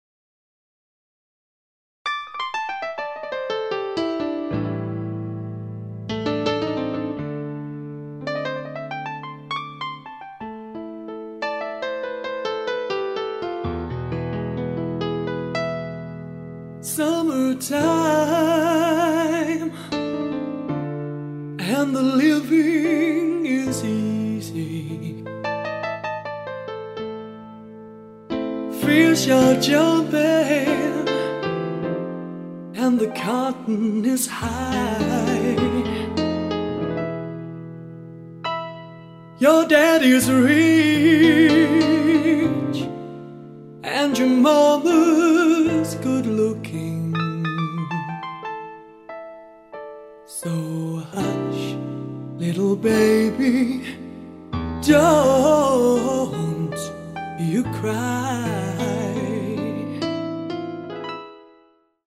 Live Piano